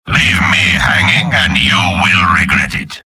Robot-filtered lines from MvM. This is an audio clip from the game Team Fortress 2 .
{{AudioTF2}} Category:Spy Robot audio responses You cannot overwrite this file.